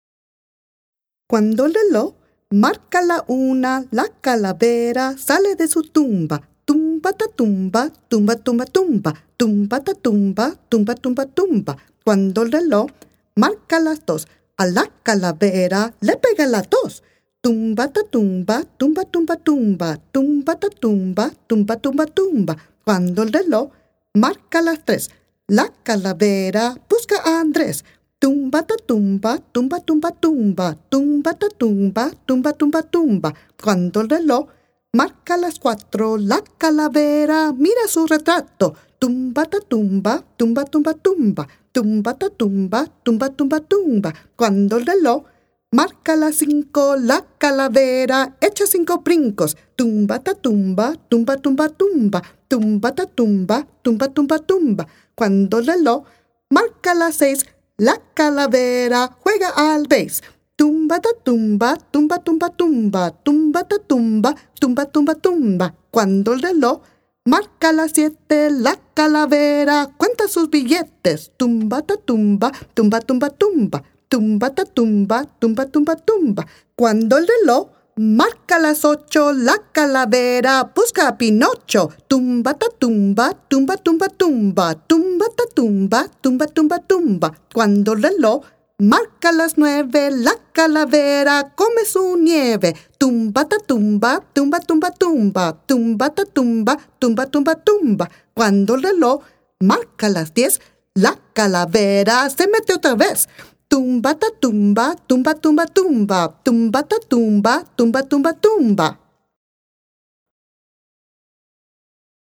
Join in the festivities with this classic Mexican chant that has delighted generations of children and families!